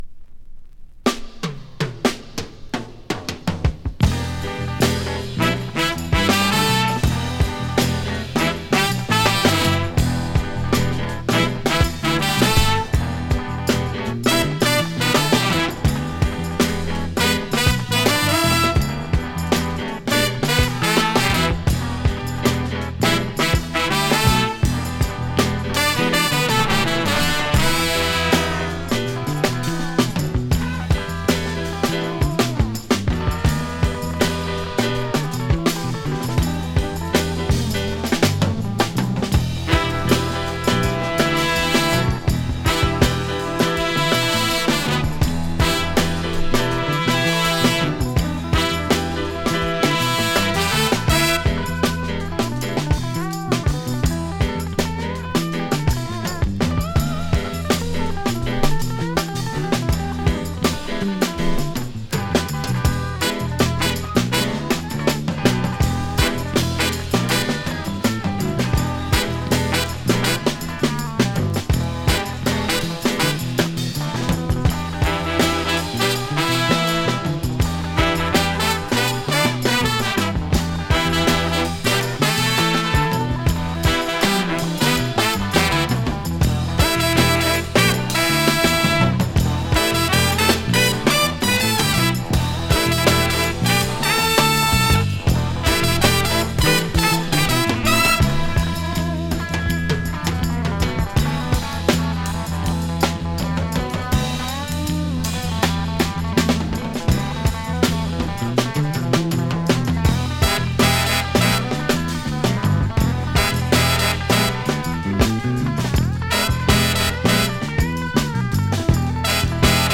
[JAZZ FUNK]
[FUNK]
Jazz funk classic!